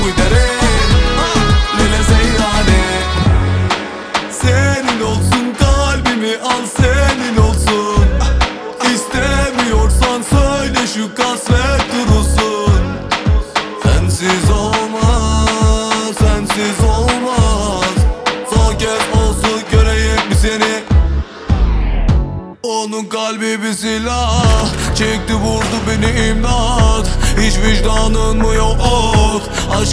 Жанр: Турецкая поп-музыка / Поп